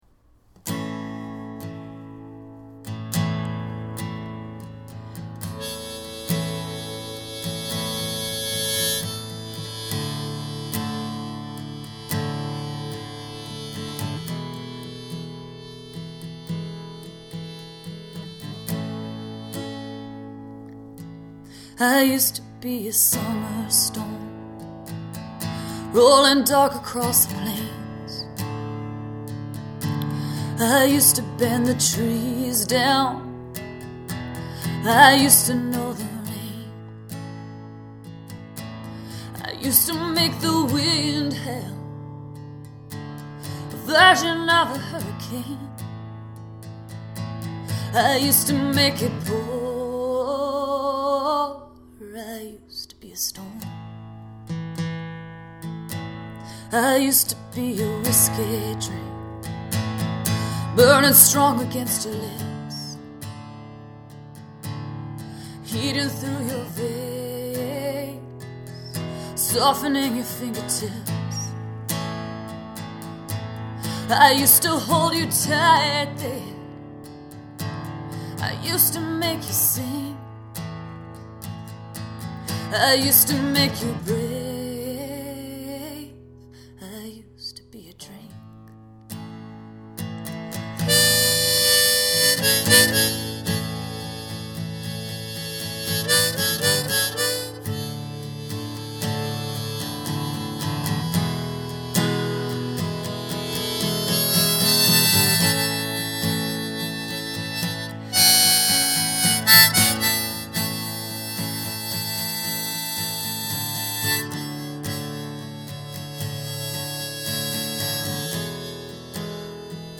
After my coffee had cooled, my emails were answered and phone calls made, I sat down behind that guitar and listened for what might come from me.
Just born in my living room on my lunch break with my laundry in piles and the dishes in the sink and no plans for supper or anything really because I wanted to sing something new, so I made this.
Living Room Session